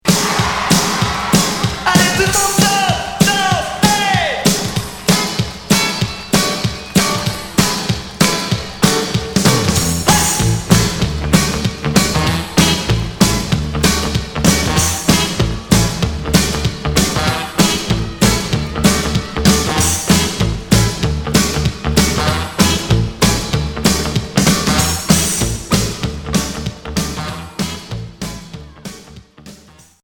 (live)
New wave Neuvième 45t retour à l'accueil